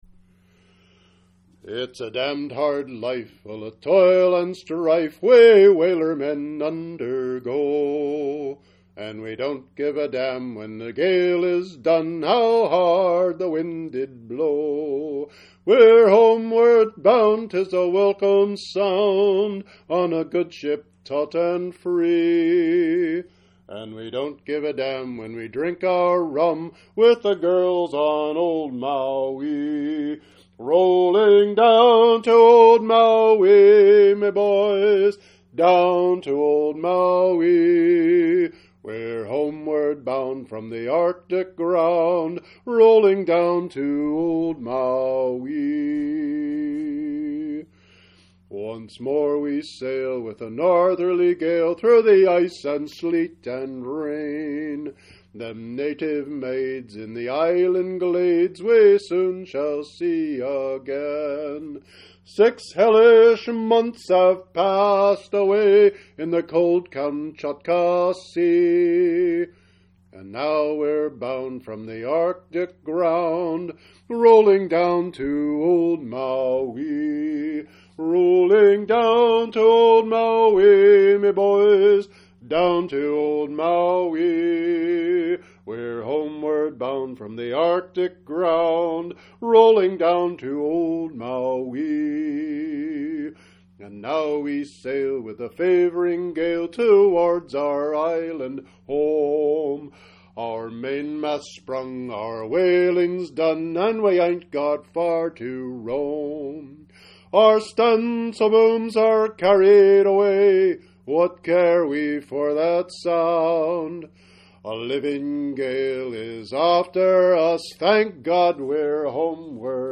Sea Songs and Chanties